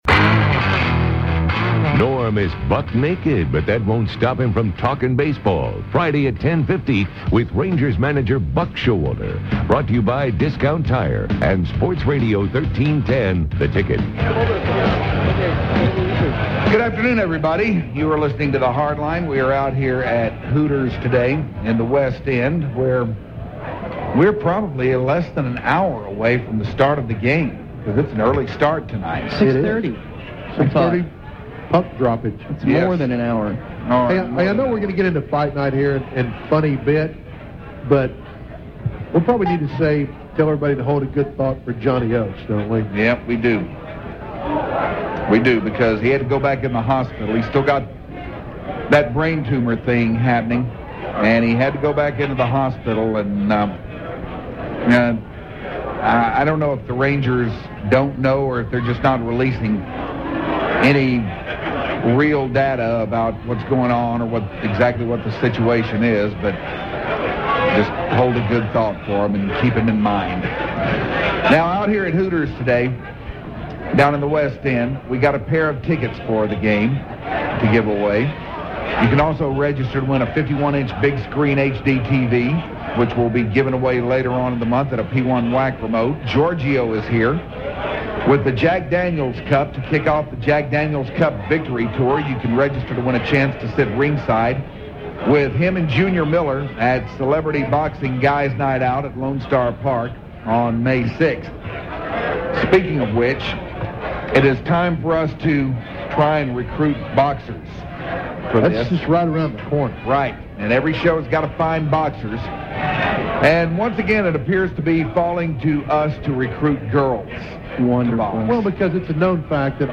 The Hardliners run a station promo highlighting all of the special Hardline guests